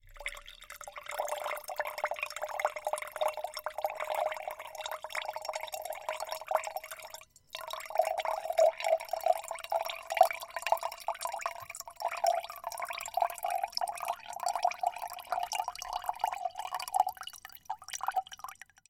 2015年3月 " 浇灌的水
描述：填充一杯水的声音记录在Yeti立体声麦克风上
Tag: 填充 玻璃 倾倒 滴落 饮料 液体 浇注